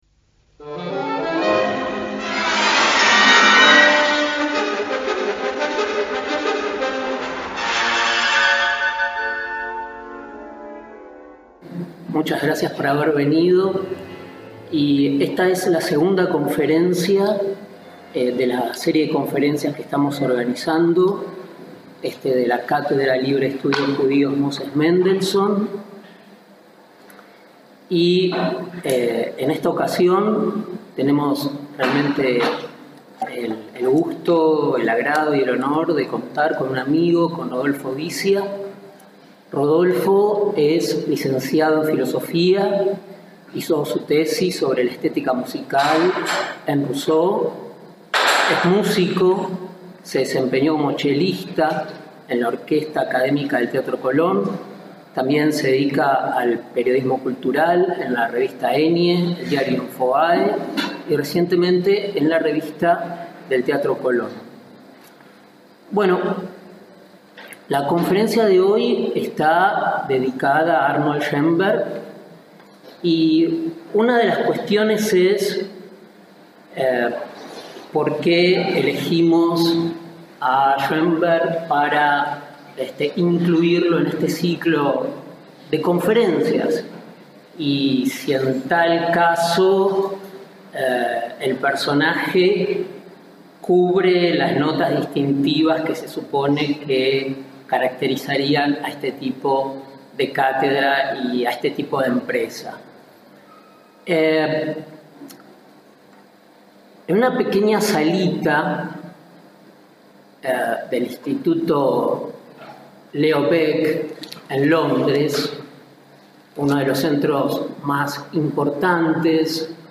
ACTO EN DIRECTO